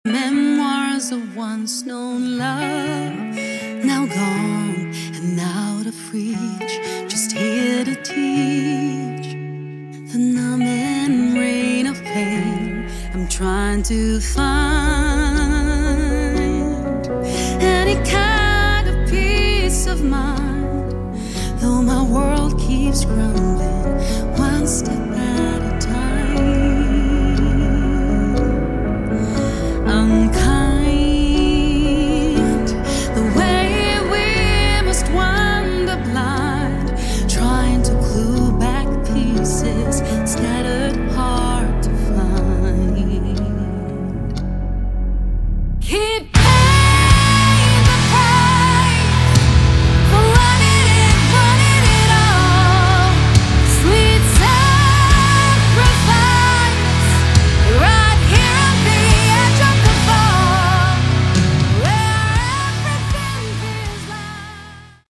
Category: Melodic Metal
vocals
guitars
bass
keyboards
drums